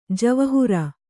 ♪ javahura